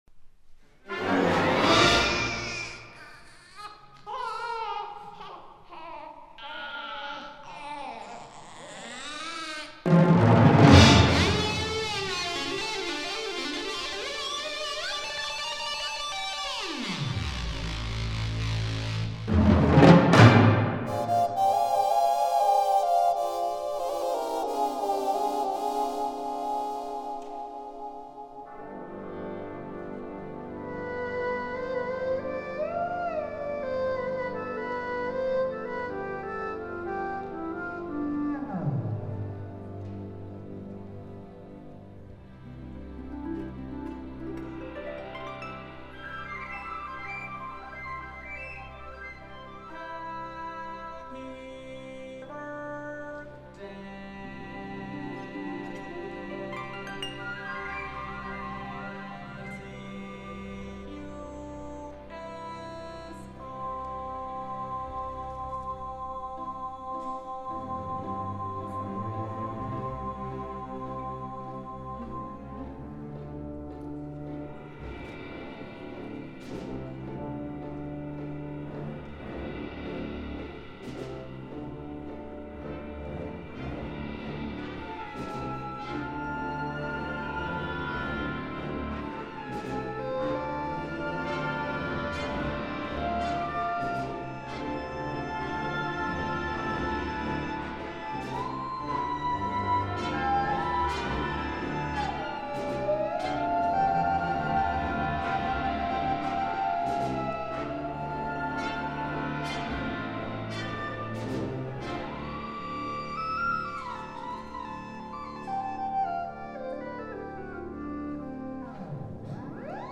He decided to feature the the Continuum in the piece, which ended up being called "Quinquagenarium". I performed it with the CU Symphony on February 19th, 2010 in the Foellinger Great Hall at Krannert Center.
It was a novelty for most of the audience to hear Eddie Van Halen guitar sounds at the beginning of an orchestral concert.